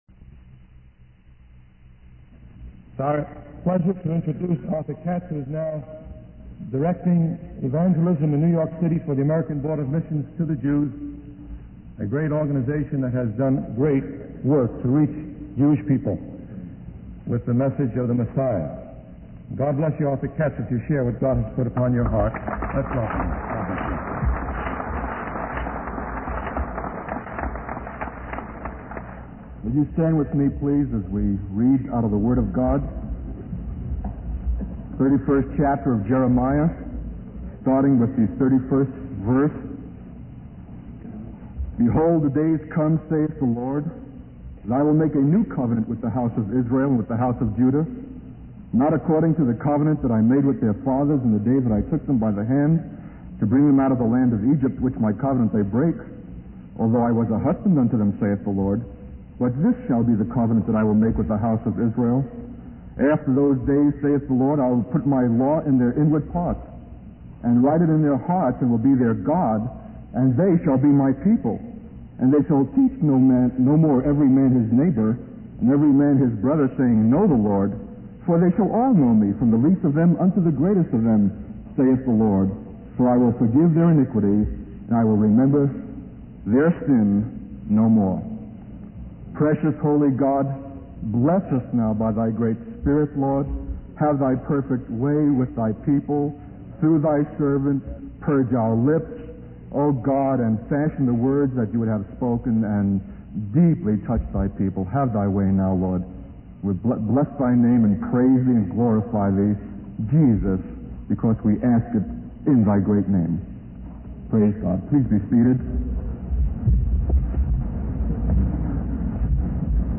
In this sermon, the speaker laments the death of four students and criticizes the tendency to point fingers at others without recognizing the flaws within oneself. He emphasizes that slogans and ceremonies are not enough to save us, even in the context of Christianity. The speaker shares his personal experience of feeling uncomfortable with the phrase 'Christ is the answer' because it seemed empty and devoid of life.